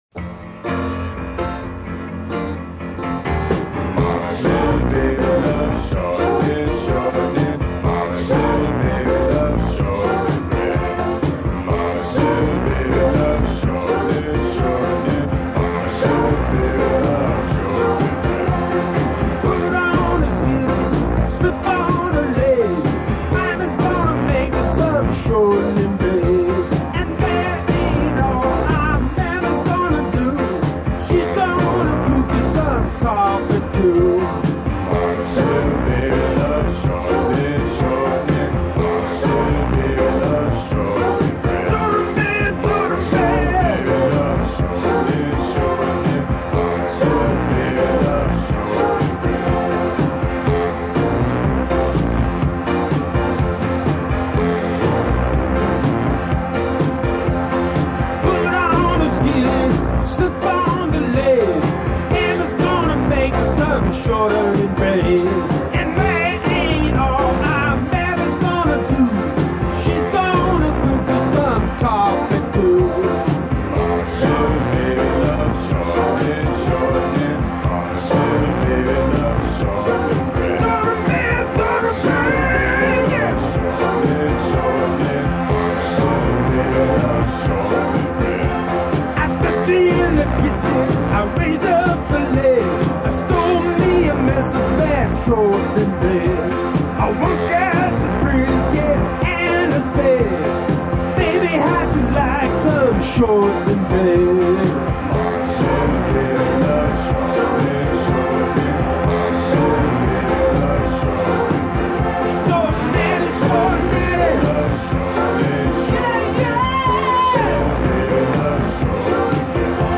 an unreleased session recording from 1973